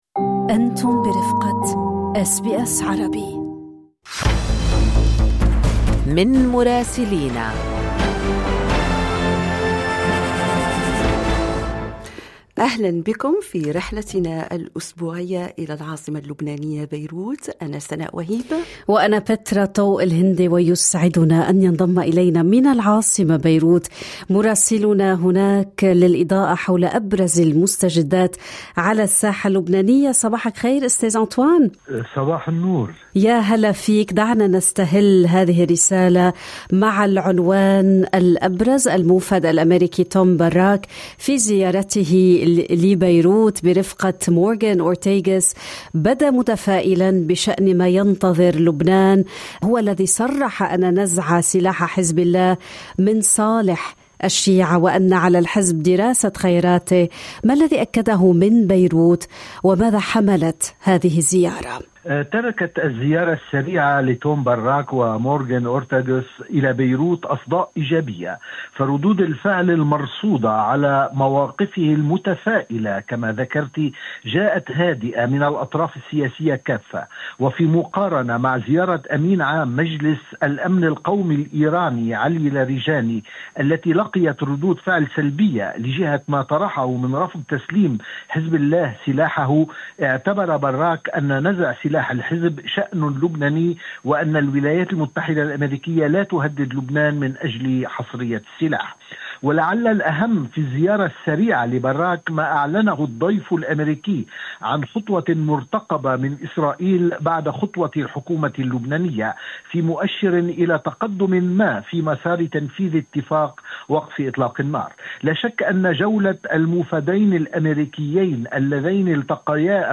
تفاؤل أميركي ورسالة غنائية من أصالة… تفاصيل المشهد اللبناني في تقرير مراسلنا في بيروت